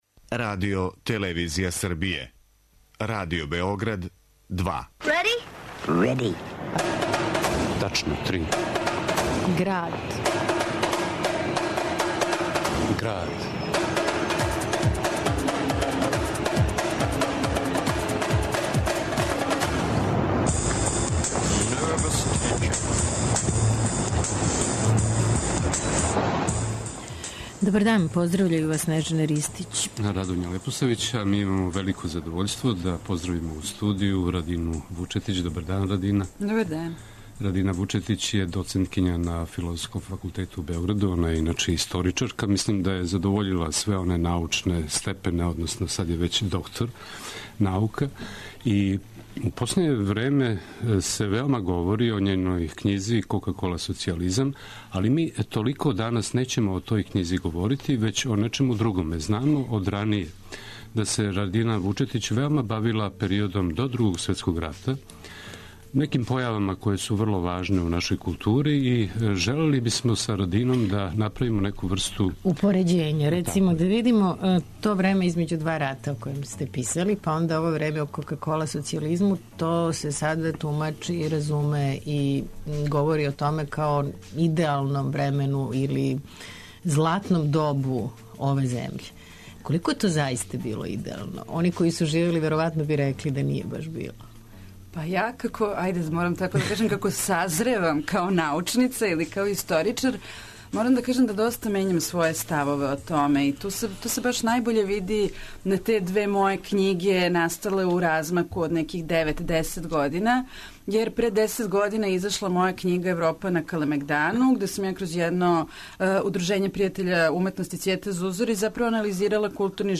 [ детаљније ] Све епизоде серијала Радио Београд 2 Хималаји лети Избор из програма Радио Београда 2 Најава културних догађаја Еколошки магазин Сезона младих 2025: Фадо нијансирање